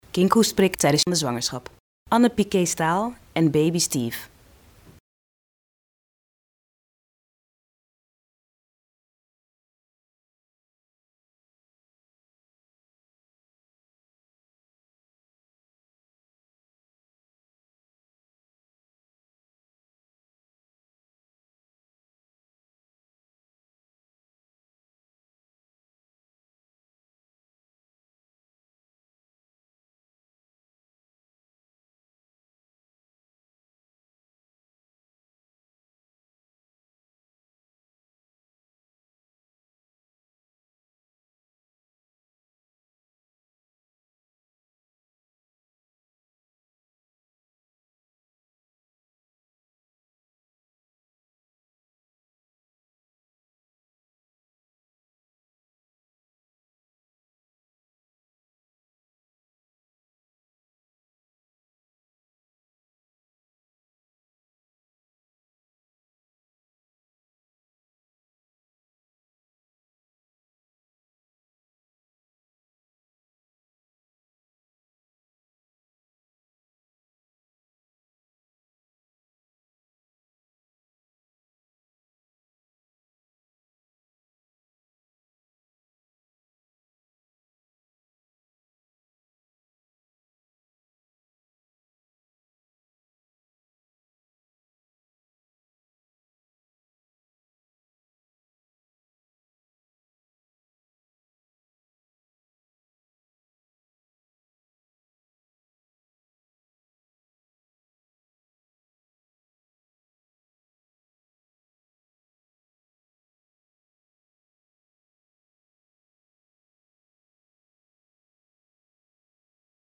In deze video wordt u door een huisarts uitgelegd waarom u de kinkhoestprik aan het eind van de zwangerschap kan nemen.